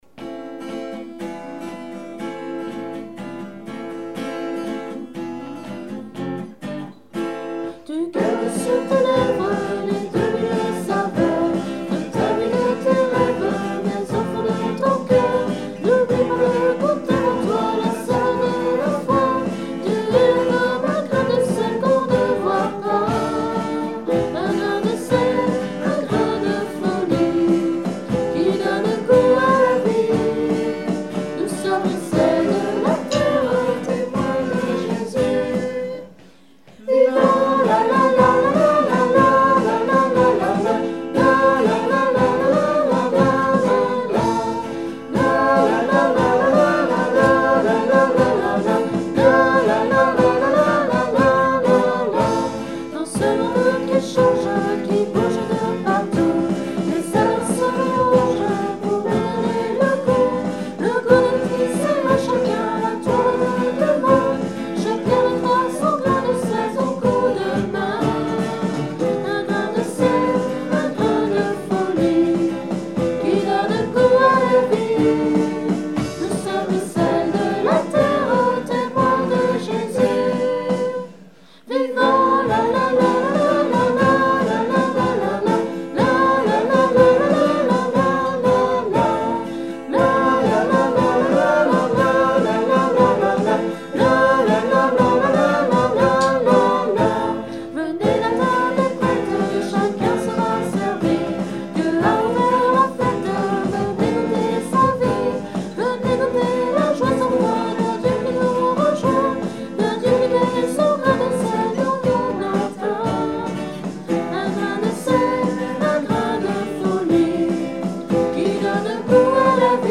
[ Messe de mariage ]